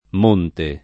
m1nte] s. m. — sim. il pers. m. stor.